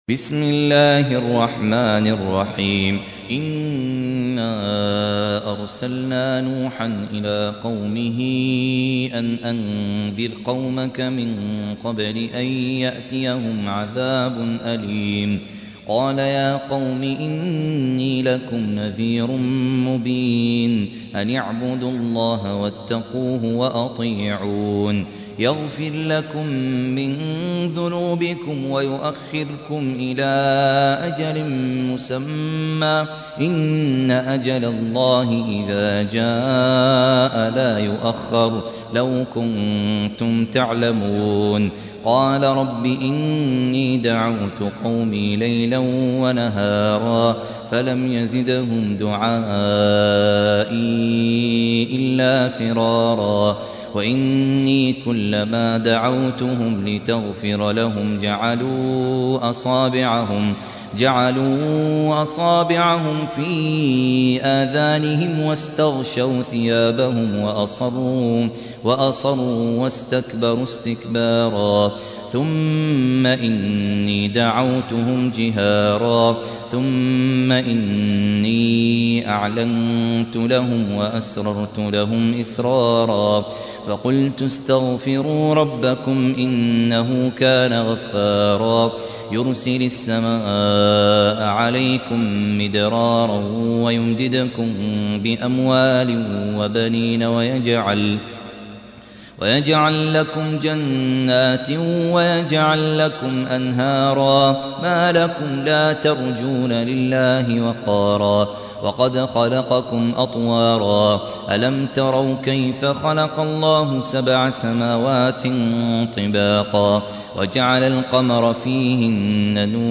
تحميل : 71. سورة نوح / القارئ خالد عبد الكافي / القرآن الكريم / موقع يا حسين